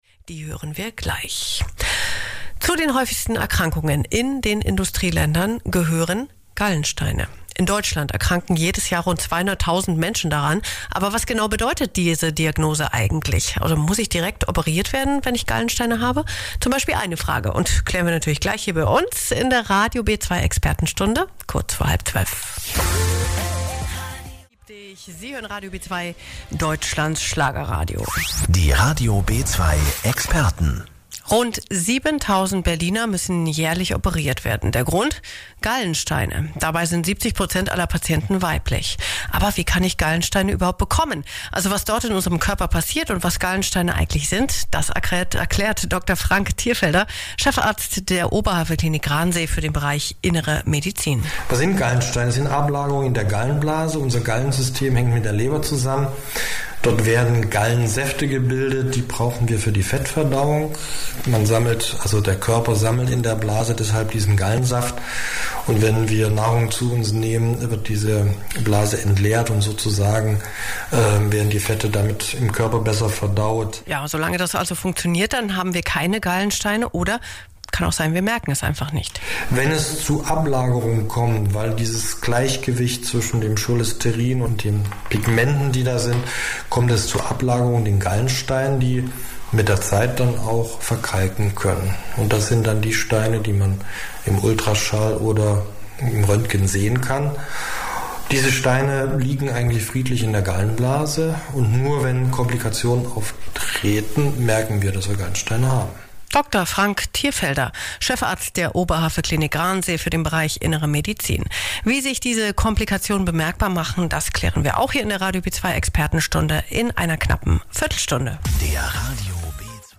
im Interview bei Radio B2.